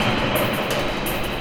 RI_DelayStack_170-03.wav